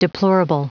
Prononciation du mot deplorable en anglais (fichier audio)
Prononciation du mot : deplorable